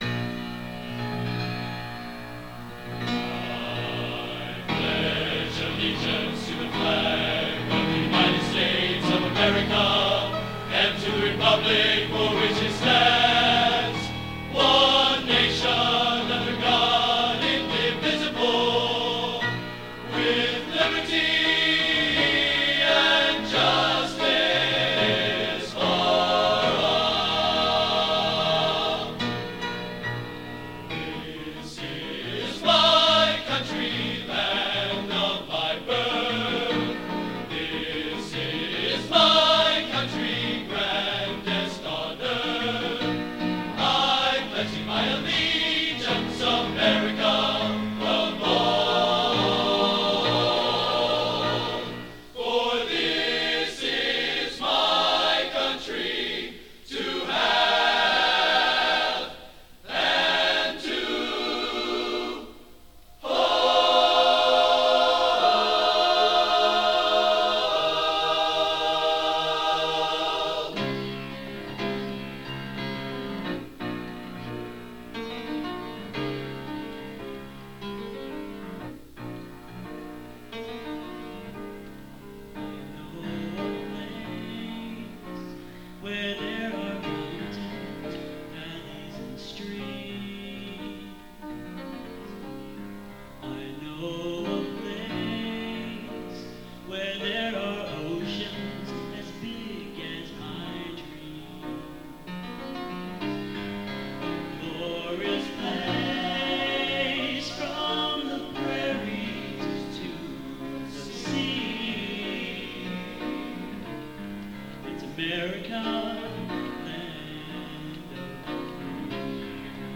Genre: Patriotic | Type: Solo